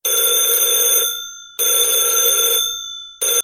描述：电话铃声的合成声。
标签： 电话 铃声 电话 合成
声道立体声